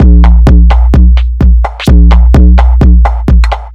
Index of /musicradar/uk-garage-samples/128bpm Lines n Loops/Beats
GA_BeatResC128-05.wav